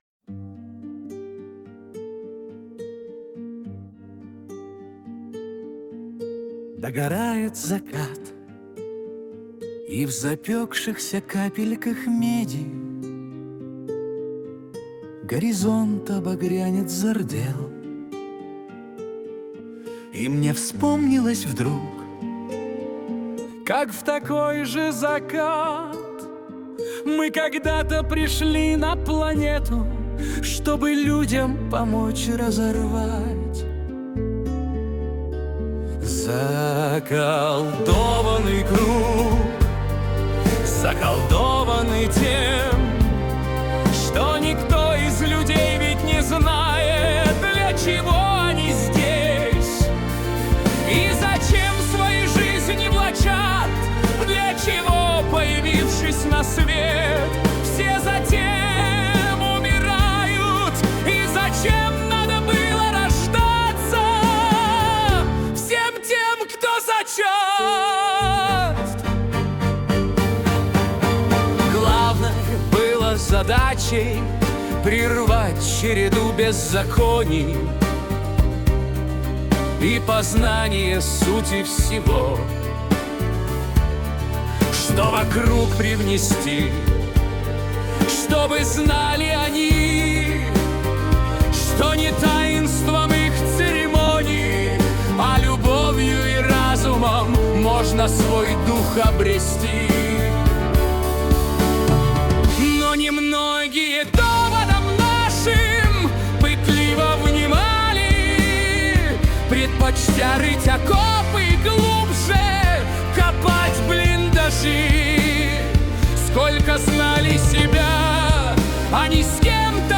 кавер-версия
Для Медитаций